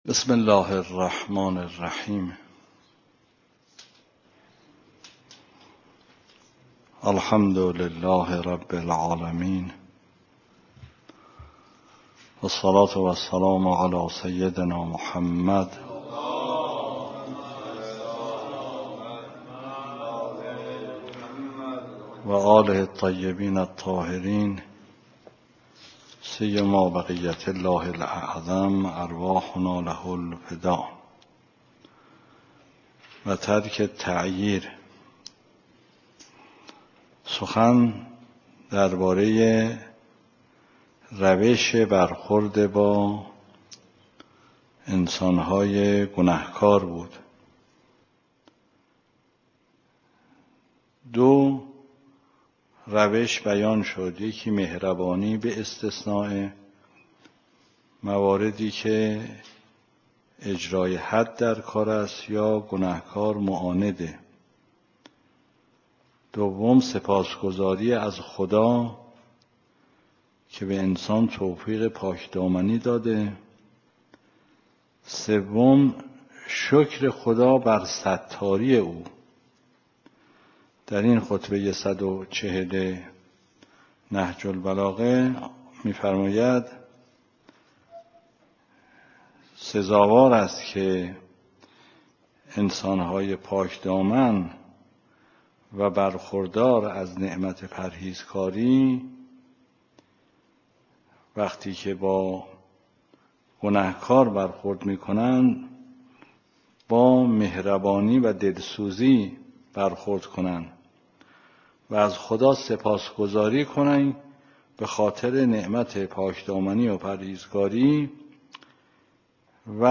به گزارش خبرنگار فرهنگی باشگاه خبرنگاران تسنیم «پویا»، حجت‌الاسلام محمد محمدی ری شهری تولیت آستان حضرت عبدالعظیم حسنی(ع) اخیراً در ابتدای جلسه درس خارج فقه خود به 3 روش برخورد با انسان‌های گناهکار اشاره کرد و گفت: نخستین روش، مهربانی است، البته به استثنای مواردی که اجرای حد در کار است یا گناهکار معاند است، دومین روش سپاسگزاری از خداست که به انسان توفیق پاکدامنی داده است و سومین روش هم شکر خدا بر ستاریت اوست.
صوت کامل بیانات حجت‌الاسلام ری شهری را در ادامه می‌شنوید: